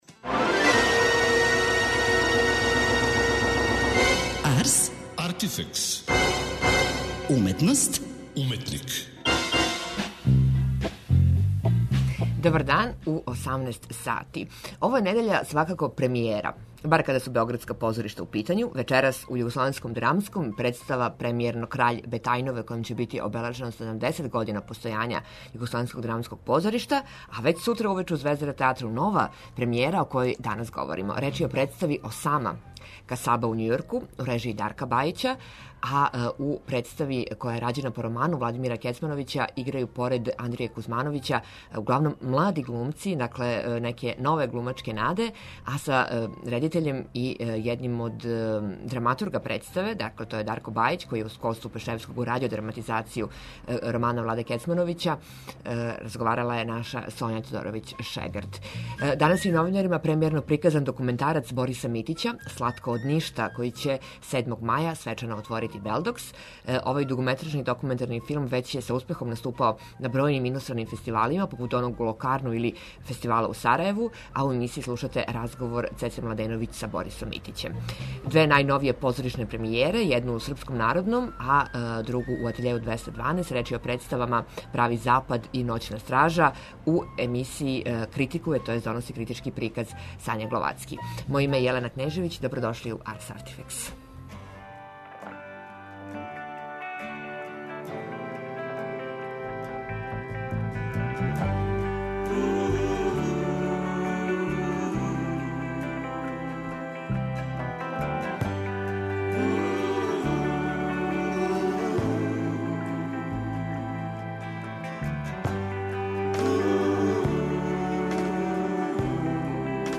У емисији ћете чути разговор